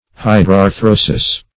Hydrarthrosis \Hy`drar*thro"sis\, n. [NL.